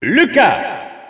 Announcer pronouncing Lucas in French PAL.
Lucas_French_Announcer_SSBU.wav